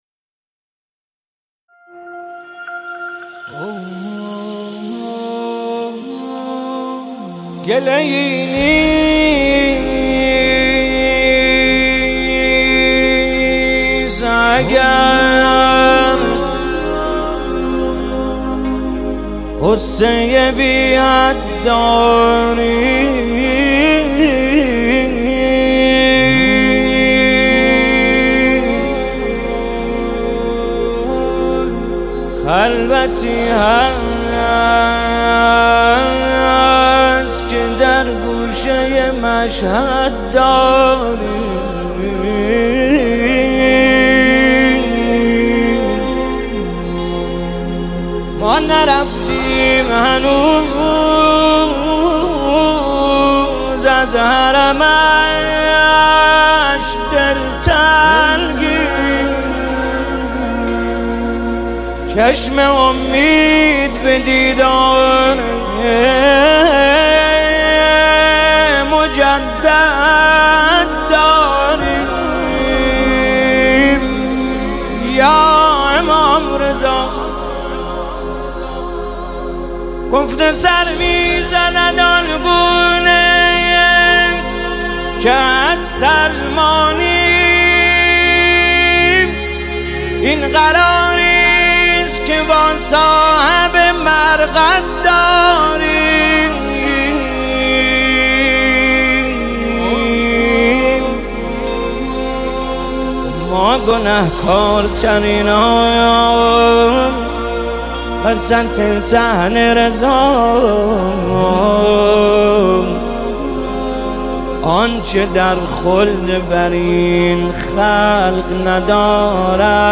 نماهنگ رضوی
با صدای دلنشین